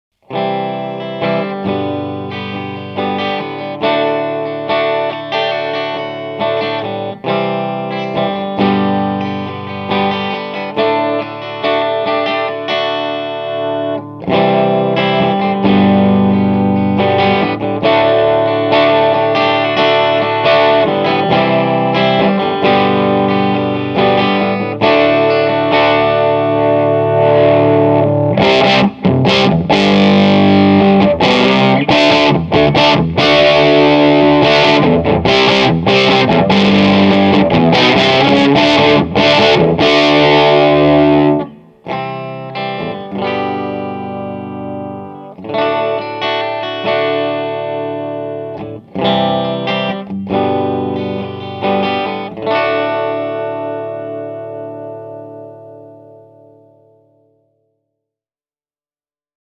Reverb and echo have been added at the mixing stage.
Studio Custom – ch 1 – drive plus guitar’s volume control
hamer-studio-ch-1-volume-control.mp3